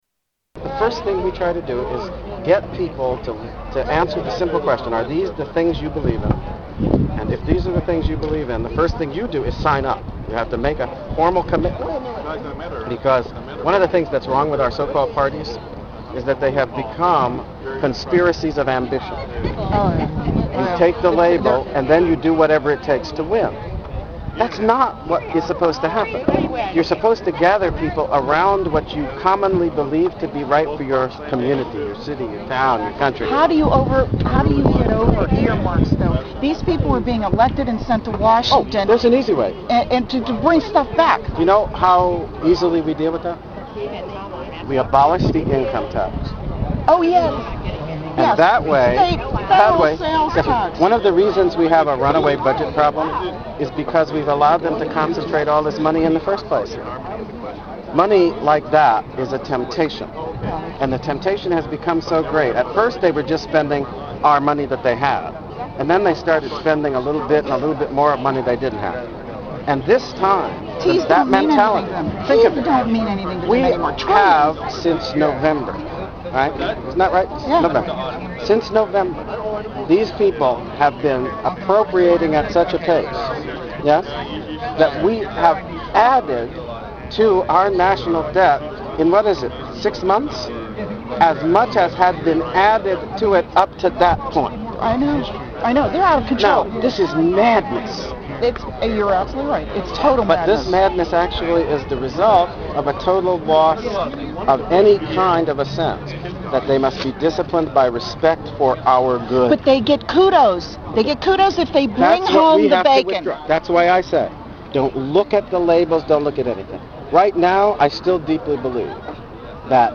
Tags: Political Alan Keyes audio Alan Keyes Alan Keyes Speeches The Tea Part